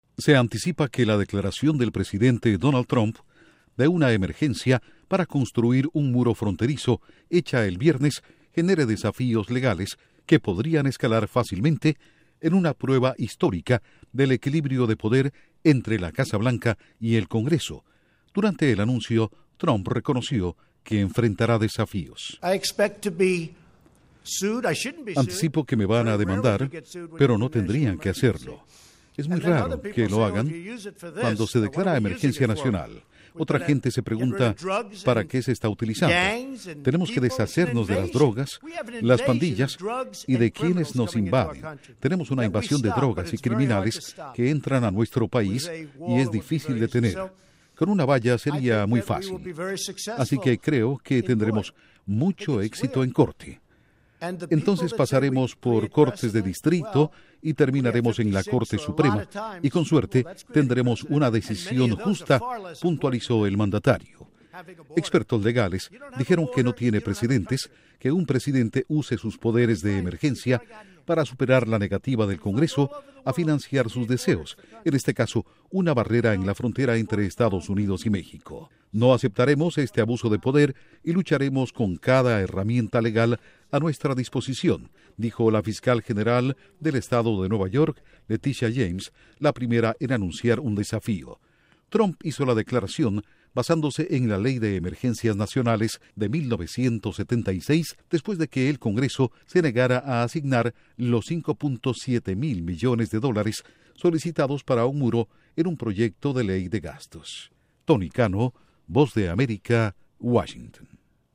Duración: 1:51 Con audios de Donald Trump/Presidente de EE.UU. Reacción de Latitia James/Fiscal general de Nueva York